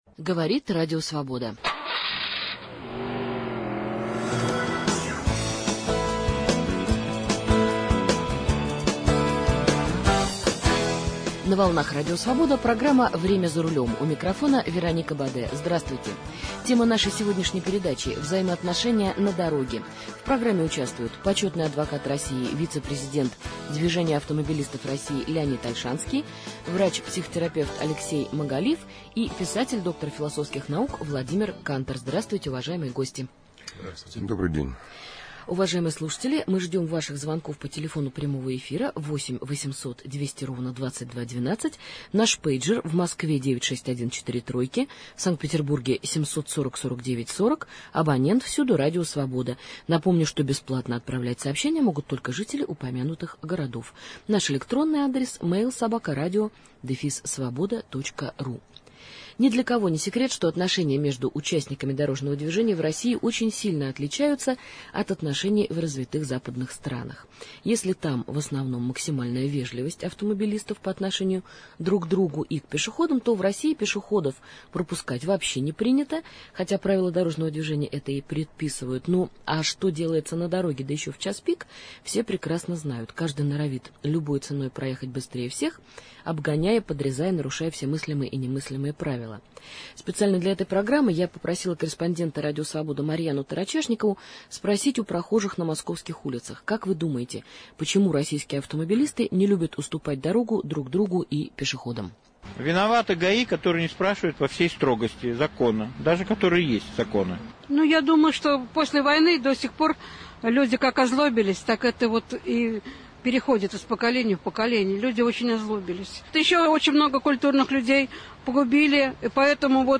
Аудио интервью разное